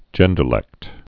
(jĕndər-lĕkt)